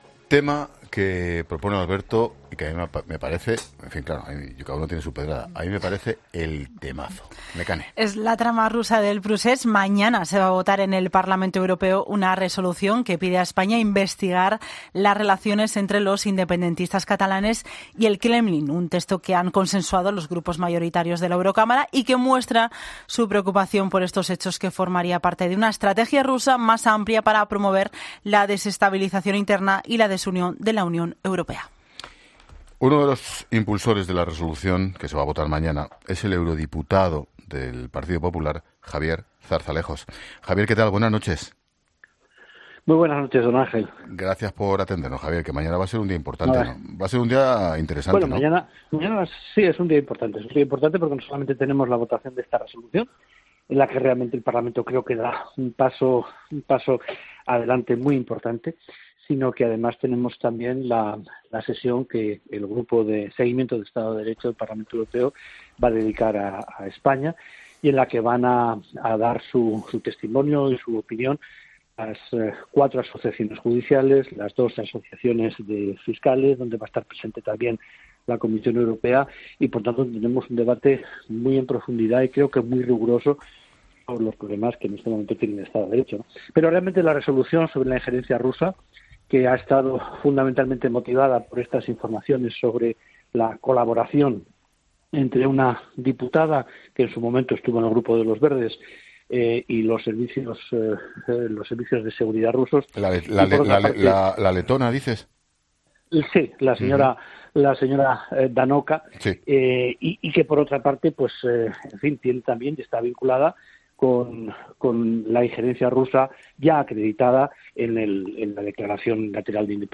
Escucha la entrevista a Javier Zarzalejos en La Linterna sobre la UE, el PSOE y la injerencia rusa